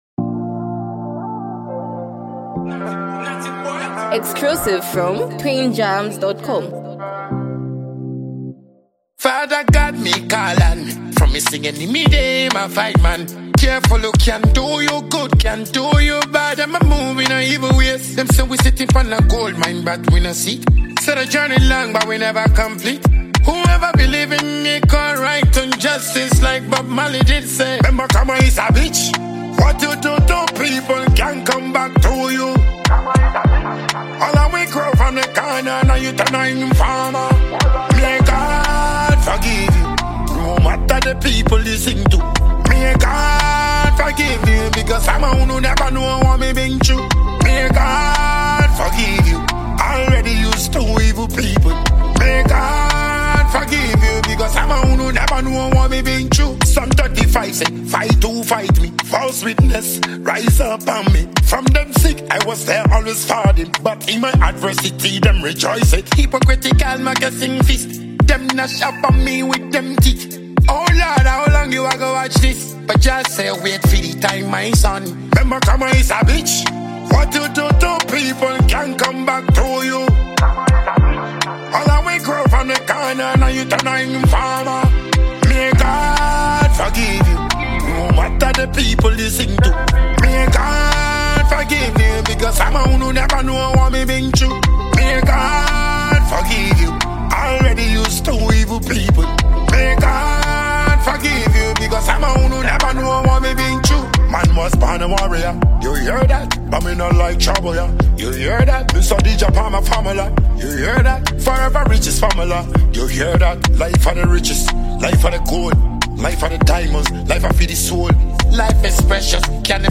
dancehall
emotional and reflective single